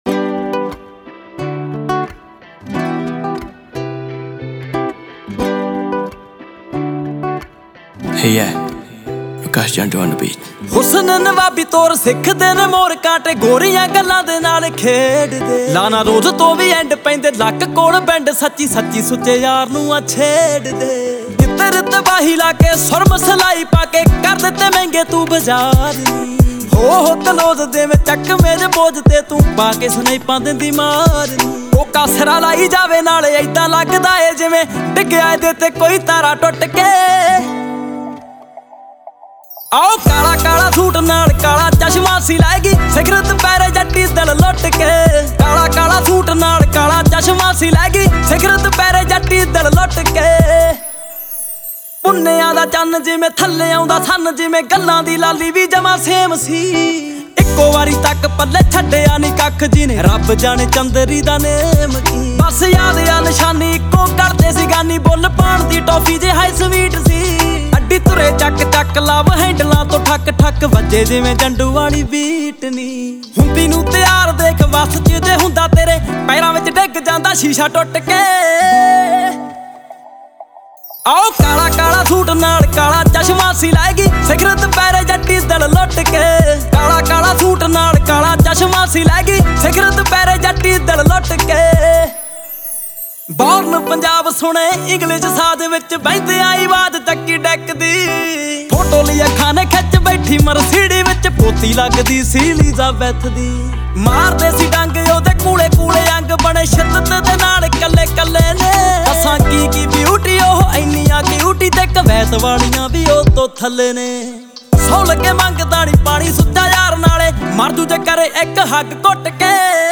Punjabi Bhangra MP3 Songs
Indian Pop